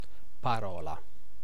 Ääntäminen
US : IPA : [ˈspiːt͡ʃ]